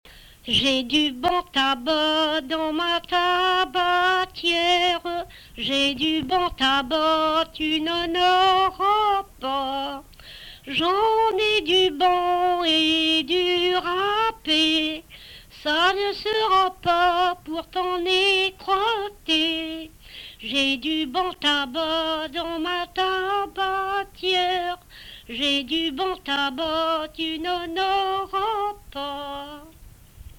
Emplacement Miquelon